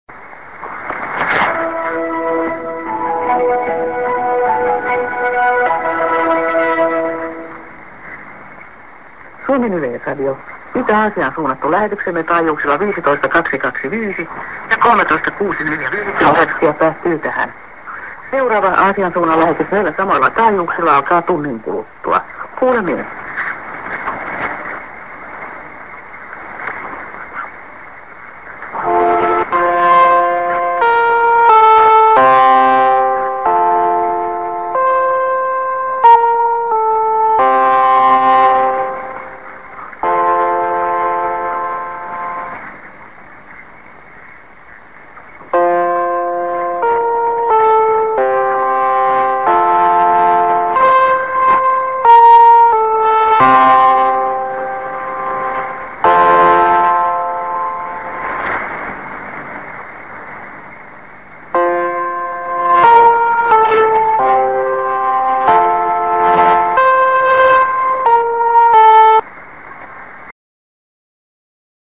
End ->SKJ(women)->30":IS